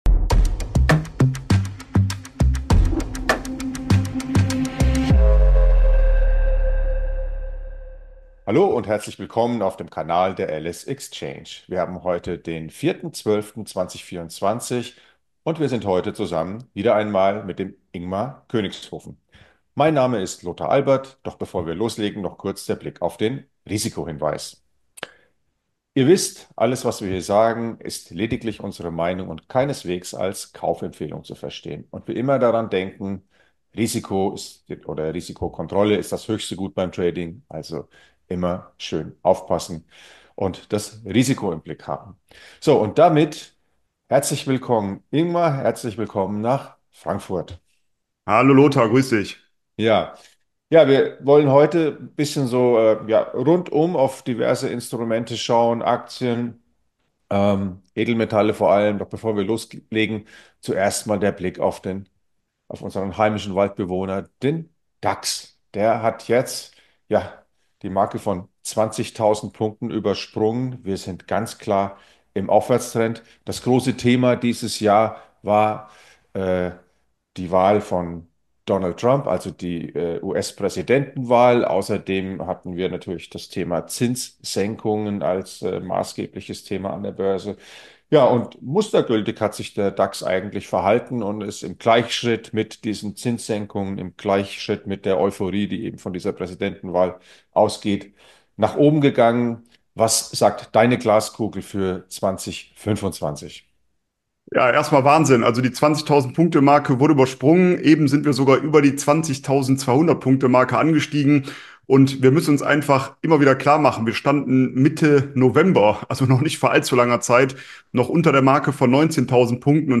Beschreibung vor 1 Jahr Mit einem Rundumblick auf die aktuellen volatilen Bewegungen an den Börsen steht im heutigen Marktinterview zunächst der DAX unter der Lupe.